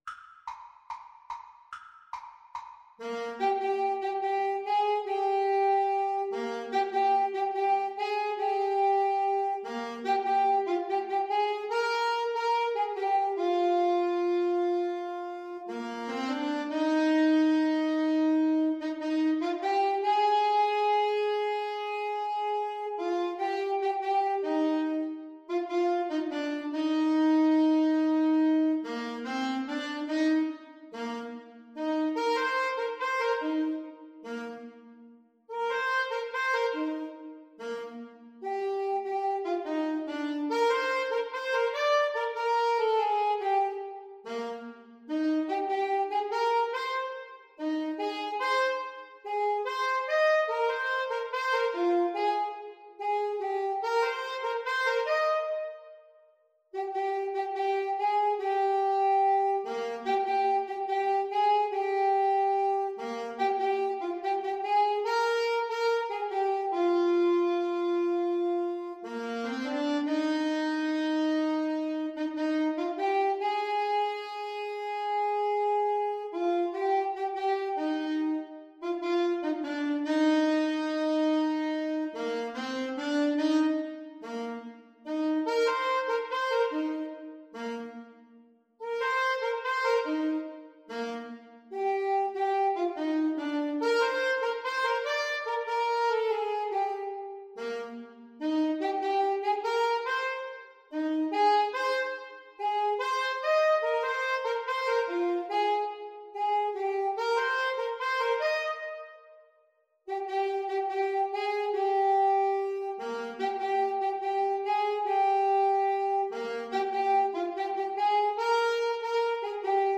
Alto SaxophoneTenor Saxophone
Gentle two in a bar = c. 72